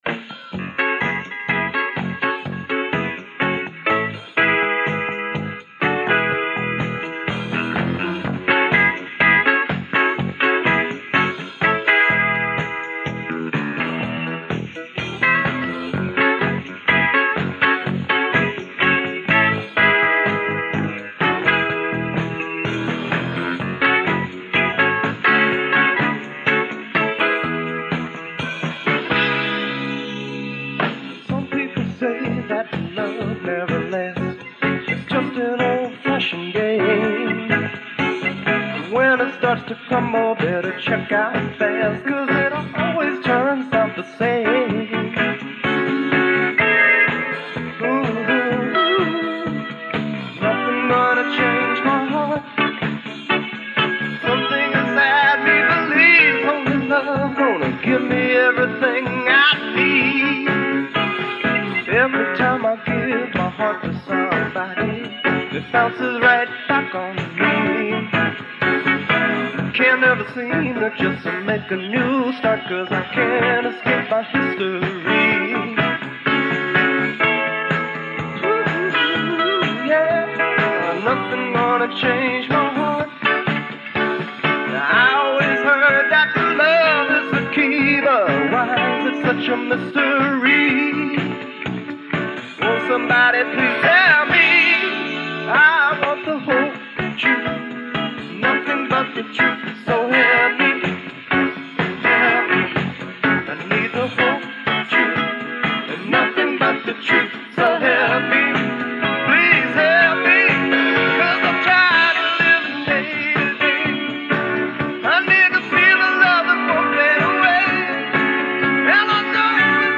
1980 BHAJANS RECORDED AT THE HOLI 1980 FESTIVAL